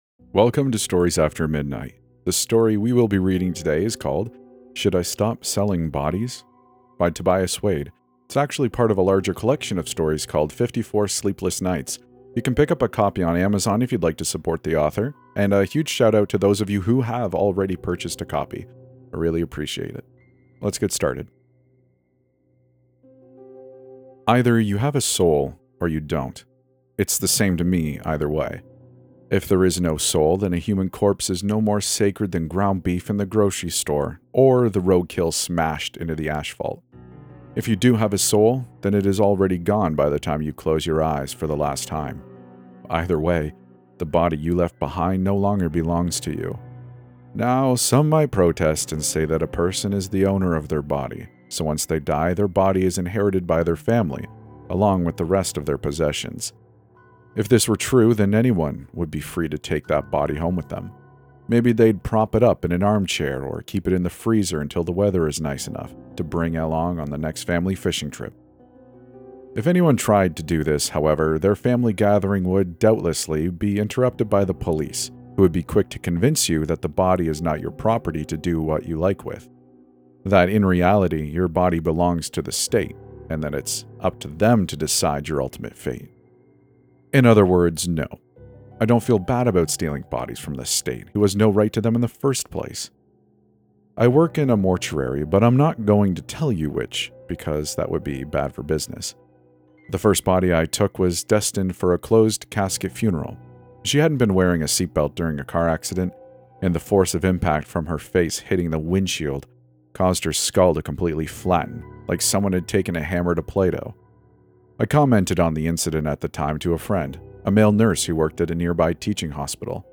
E338 | Should I Stop Selling the Bodies? | 54 Sleepless Nights | Creepypasta